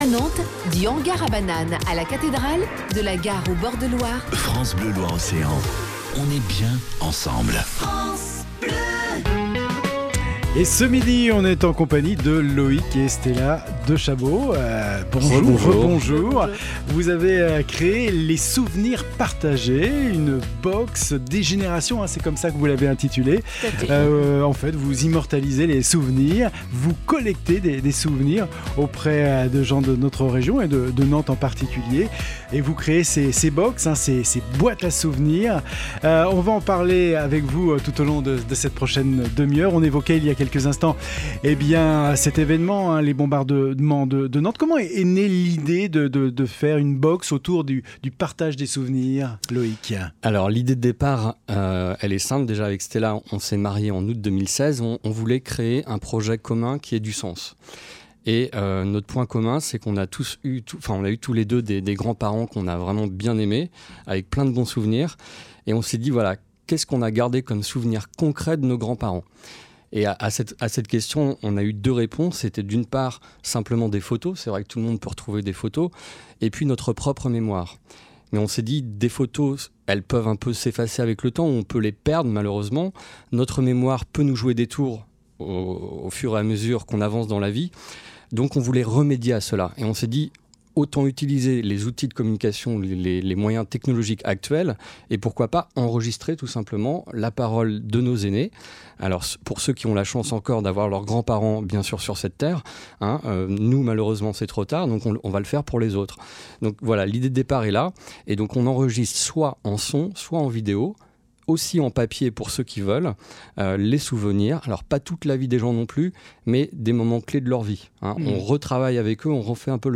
Vendredi 26 Janvier 2018, nous étions en direct sur l’antenne de France Bleu Loire- Océan, à Nantes !
ITW-FRANCE-BLEU-LES-SOUVENIRS-PARTAGES-JANVIER-2018.mp3